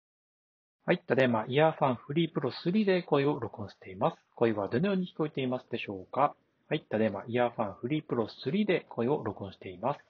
・鮮明で聞き取りやすい通話品質
ENCノイキャン技術で、周囲の騒音もしっかり低減してくれます。
ノイキャンON / 室内
earfun-freepro3-koedake.m4a